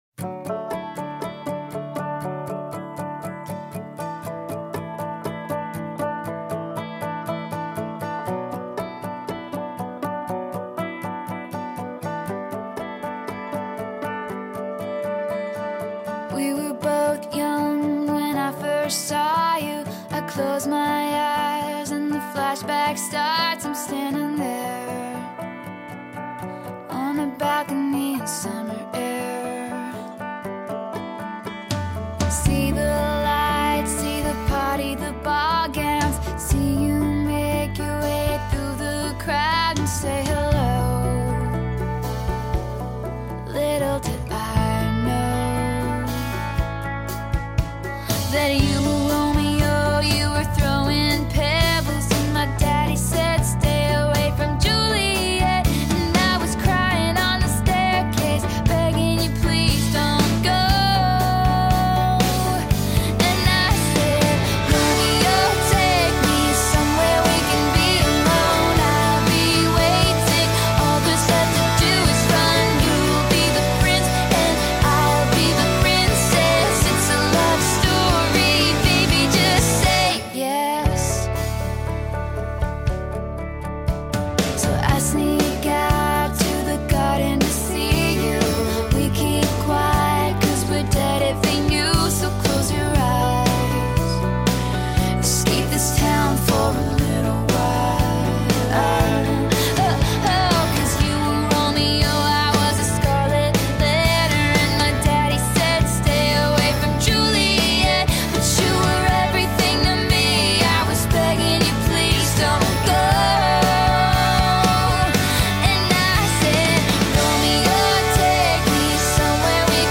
tonart A (original)